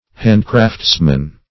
handcraftsman - definition of handcraftsman - synonyms, pronunciation, spelling from Free Dictionary Search Result for " handcraftsman" : The Collaborative International Dictionary of English v.0.48: Handcraftsman \Hand"crafts`man\ (-man), n.; pl. -men (-men). A handicraftsman.